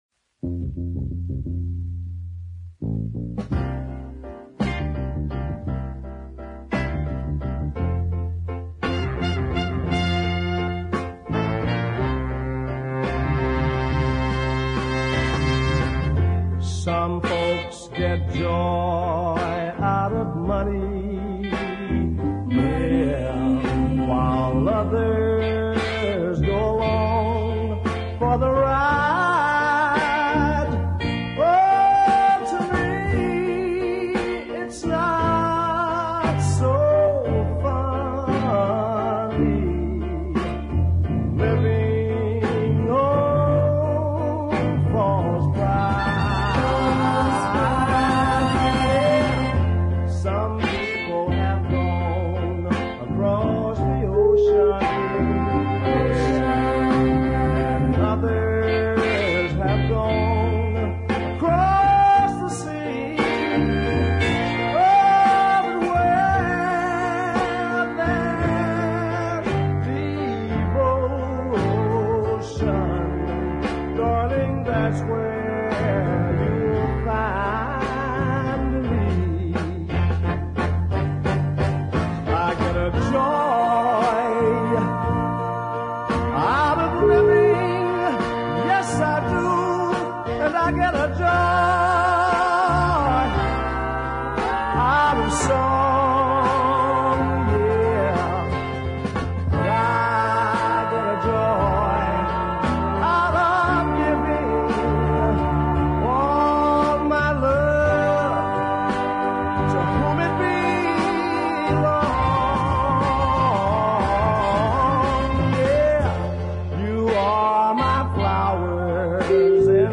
rich, melodic baritone